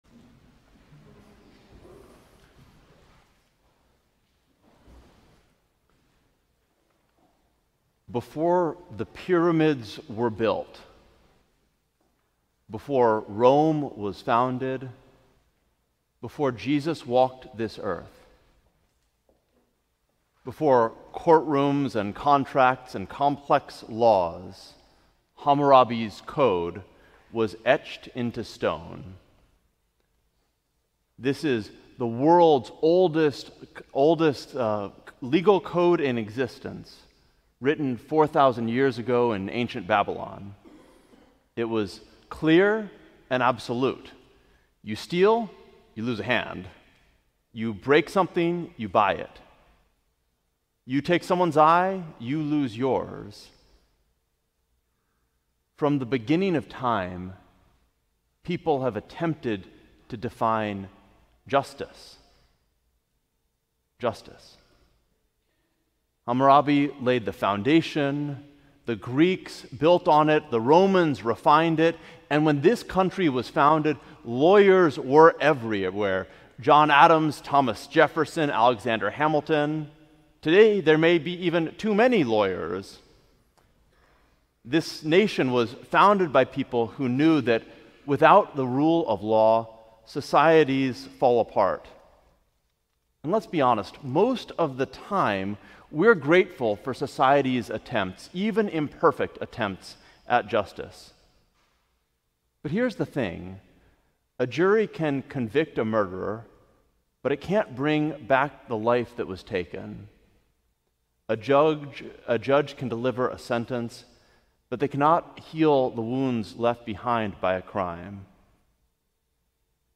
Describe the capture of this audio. Fourth Sunday in Lent. Messages of Hope from the Heart of Jacksonville Welcome to the sermon podcast of St. John’s Cathedral, an affirming and inclusive community rooted in the vibrant Urban Core of Jacksonville, FL.